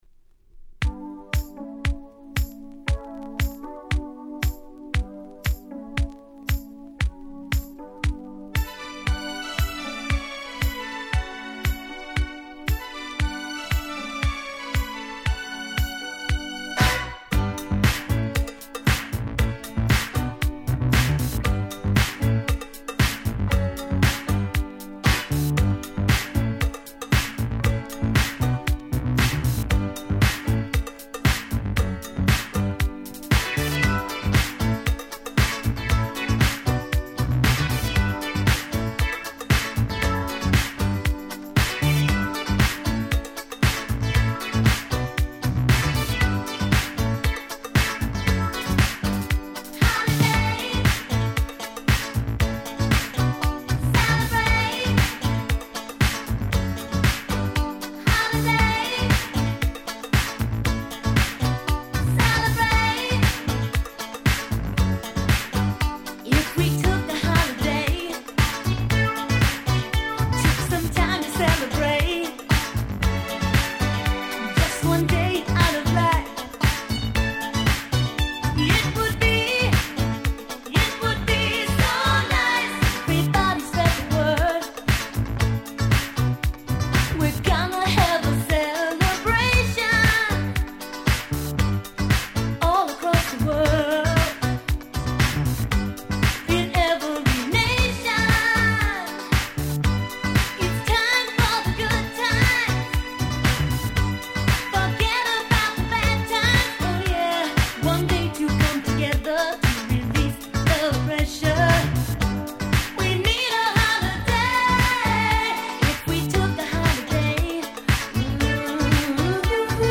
80's R&B/Disco Classic !!